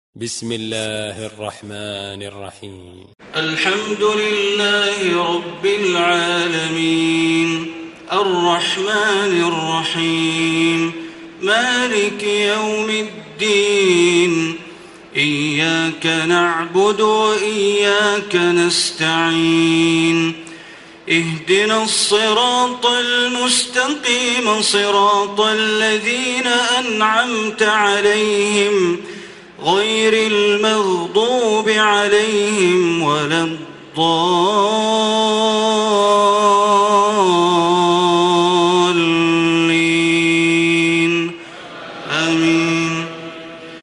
Surah Fatiha Recitation by Sheikh Bandar Baleela
Surah Fatiha, listen online mp3 tilawat / recitation in Arabic in the beautiful voice of Imam e Kaaba Sheikh Bandar Baleela.